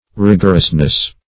Rig"or*ous*ness, n.